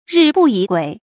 日不移晷 注音： ㄖㄧˋ ㄅㄨˋ ㄧˊ ㄍㄨㄟˇ 讀音讀法： 意思解釋： 比喻只一剎那，非常迅速。